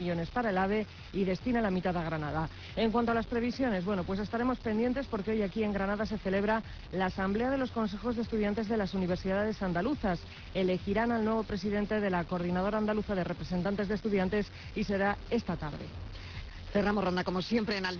Cadena Cope – LA MAÑANA (MAGAZINE): ENTREVISTA